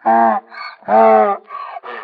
PixelPerfectionCE/assets/minecraft/sounds/mob/horse/donkey/idle3.ogg at f70e430651e6047ee744ca67b8d410f1357b5dba